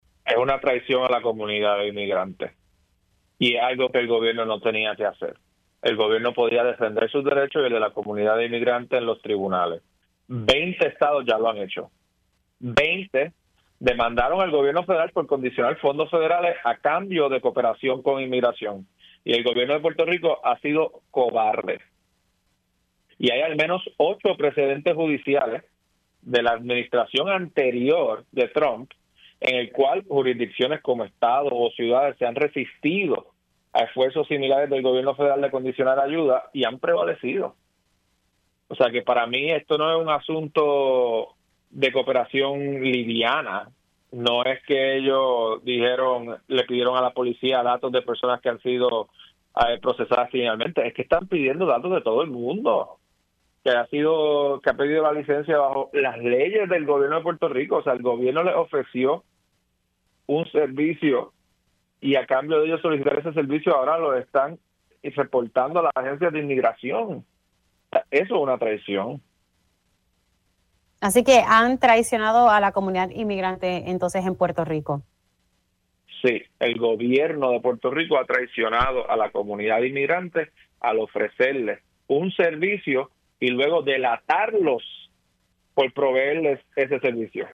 Y hay al menos ocho precedentes judiciales de la administración anterior de Trump, en el cual jurisdicciones como estados o ciudades se han resistido a esfuerzos similares del Gobierno federal de condicionar ayuda y han prevalecido“, afirmó en Pega’os en la Mañana.